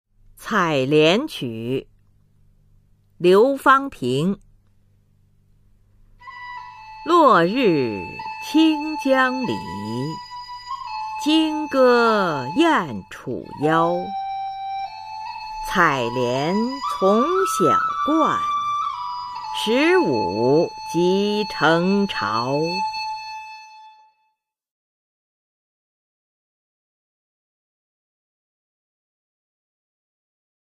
[隋唐诗词诵读]王勃-采莲曲 配乐诗朗诵